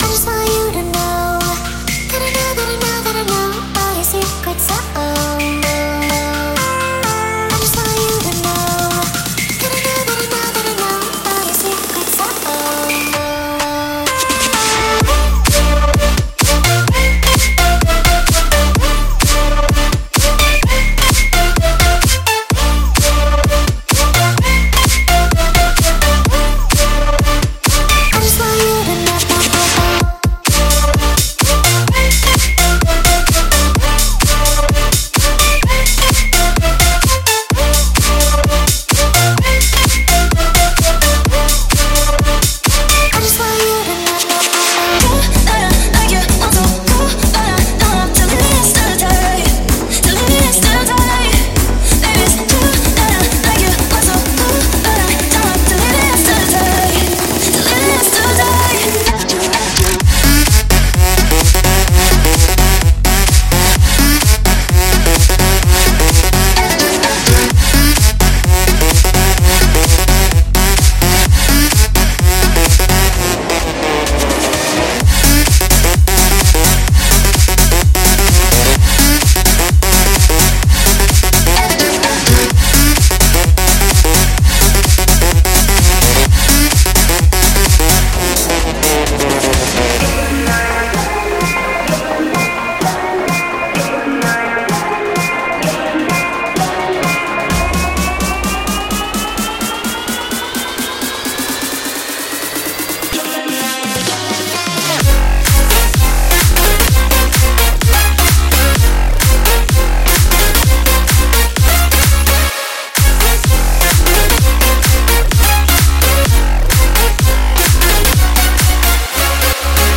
-335一枪打鼓